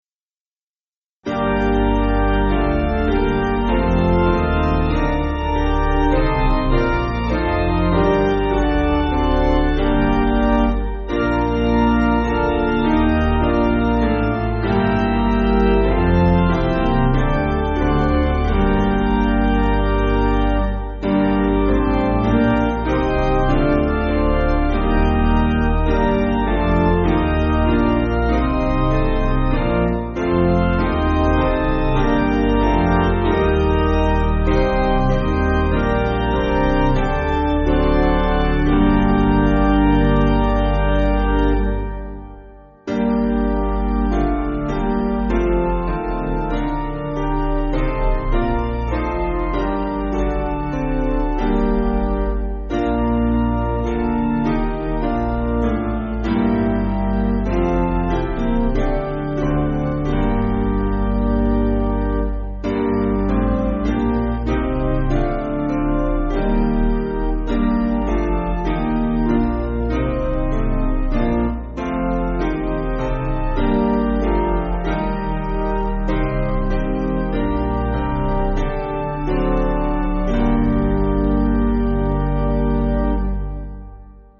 Basic Piano & Organ
(CM)   4/Gm